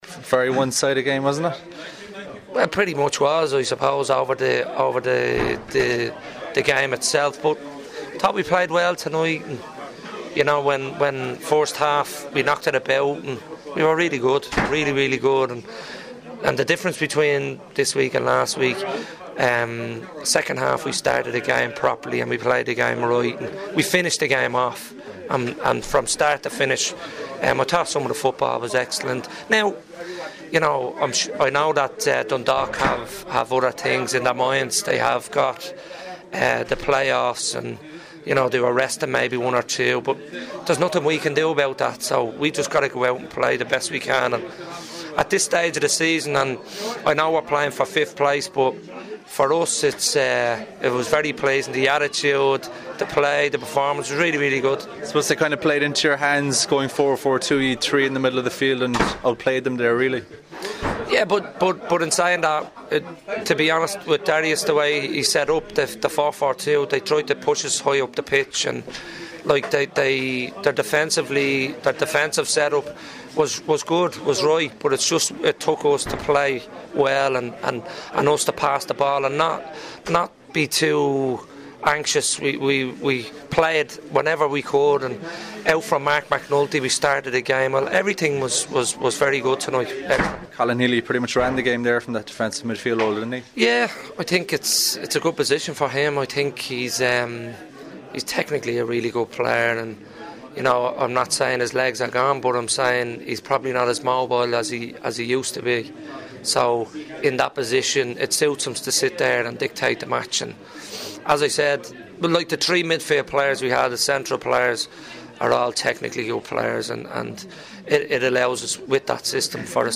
speaking after their 3-0 win over Dundalk